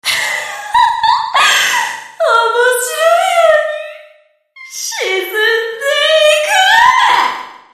潜水鮫水鬼 語音1